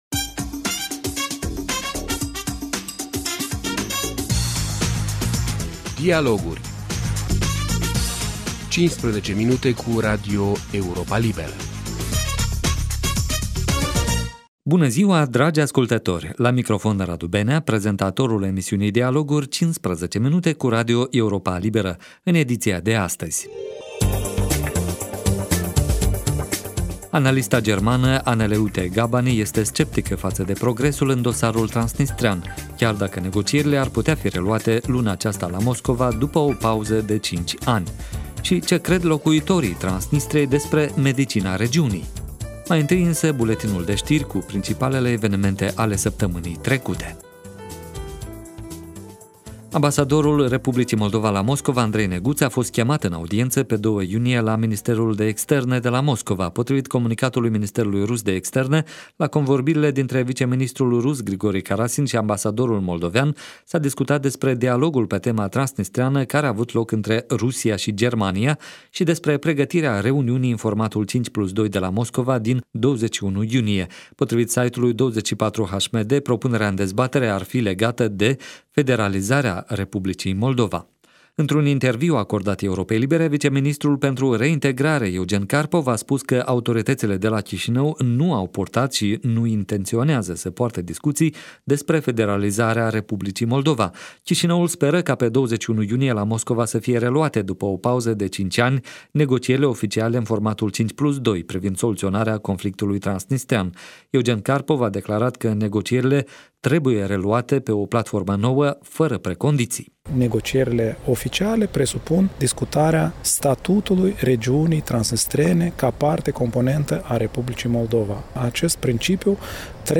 sceptică faţă de progresul în dosarul transnistrean şi un Vox populi despre medicina regiunii.